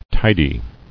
[ti·dy]